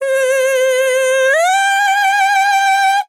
TEN VOCAL FILL 18 Sample
Categories: Vocals Tags: dry, english, female, fill, sample, TEN VOCAL FILL, Tension
POLI-VOCAL-Fills-100bpm-A-18.wav